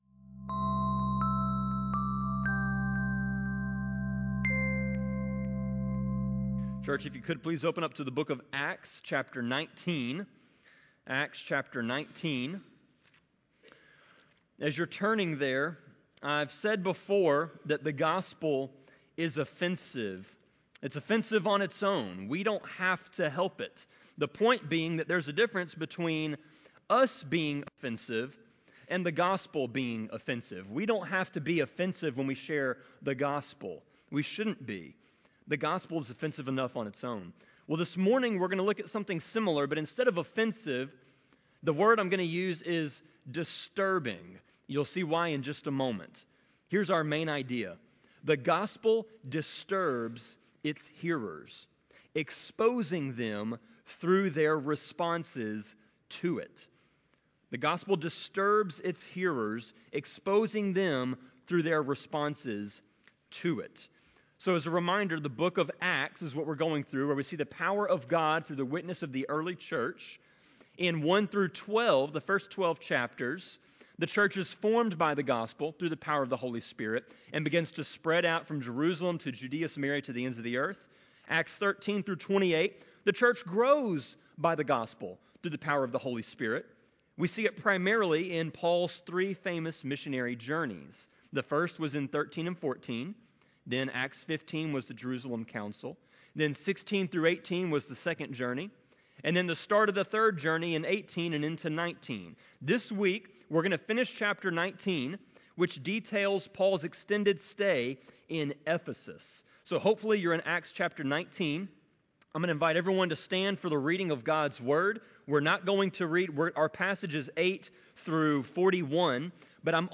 Sermon-24.8.25-CD.mp3